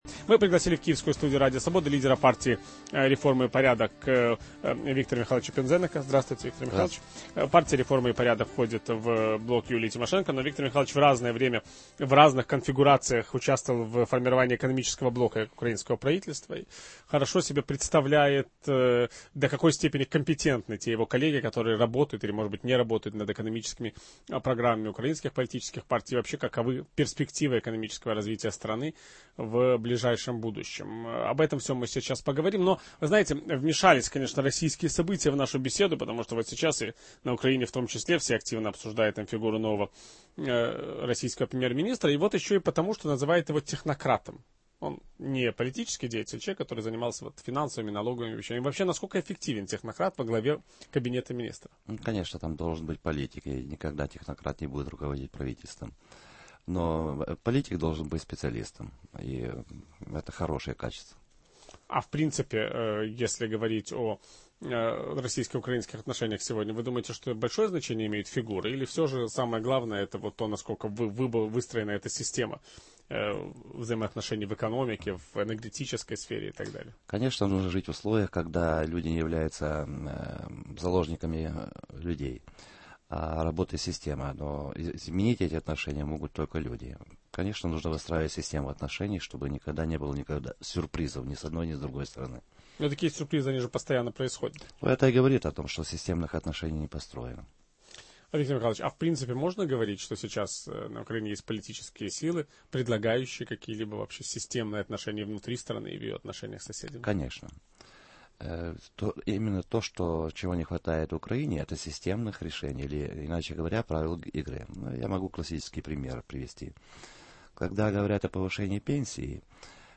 Гость киевской студии Радио Свобода - лидер Партии "Реформы и порядок" Виктор Пинзеник